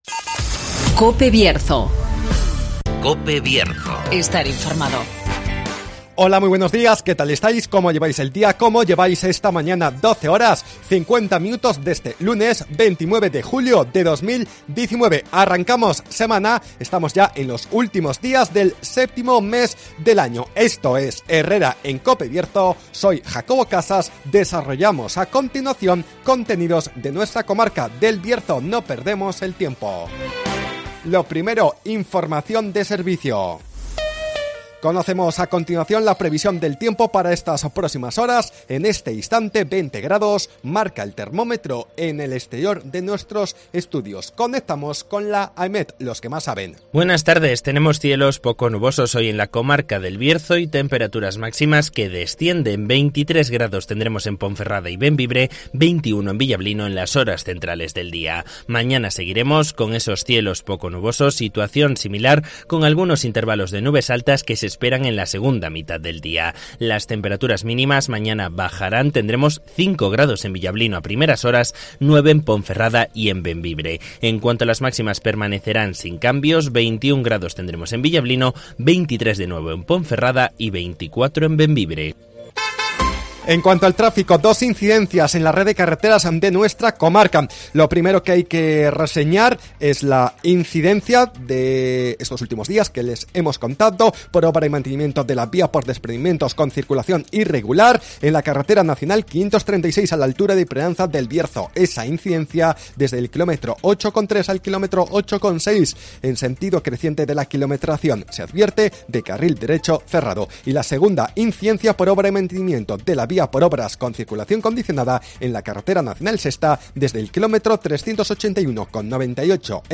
AUDIO: Repasamos la actualidad y realidad del Bierzo. Espacio comarcal de actualidad, entrevistas y entretenimiento.